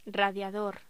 Locución: Radiador
Sonidos: Voz humana